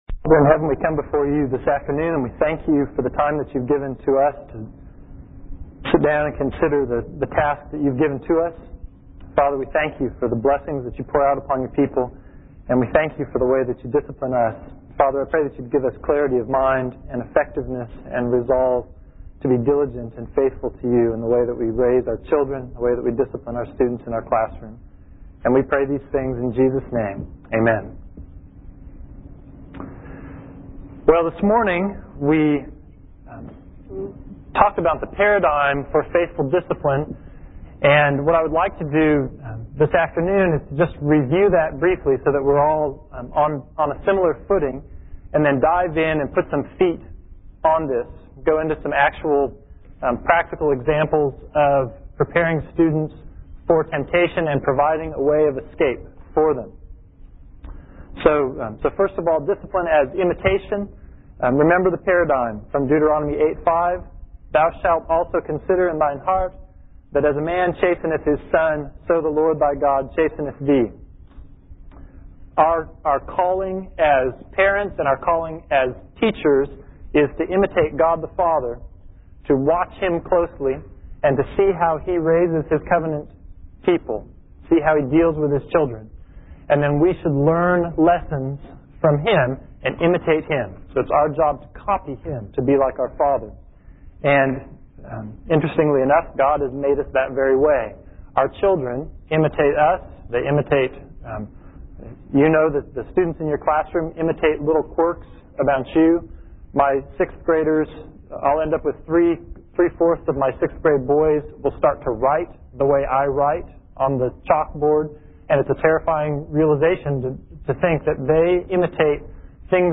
2003 Workshop Talk | 1:01:13 | All Grade Levels, Leadership & Strategic, General Classroom, Virtue, Character, Discipline
Additional Materials The Association of Classical & Christian Schools presents Repairing the Ruins, the ACCS annual conference, copyright ACCS.